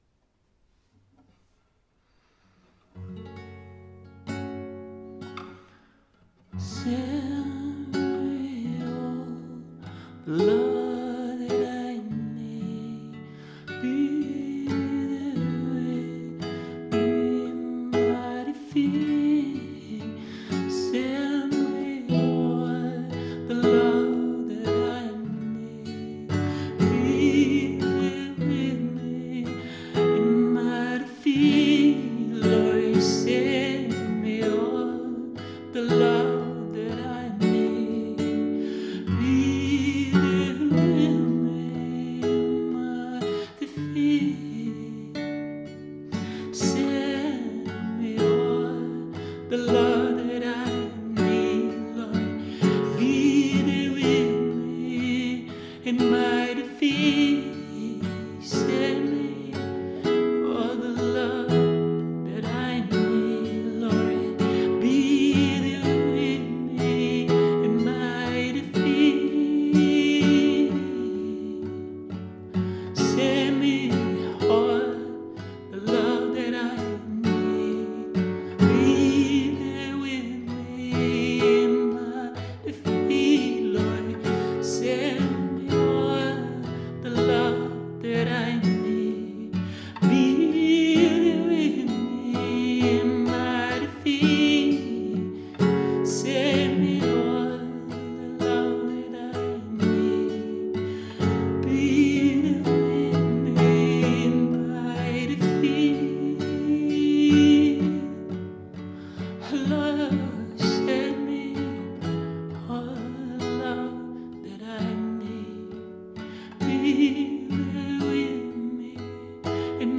…hier ein kleiner Song, der Sehnsucht nach Gottes Weg ausdrücken soll
Mittlerweile schreibe ich christliche Lieder auf Englisch.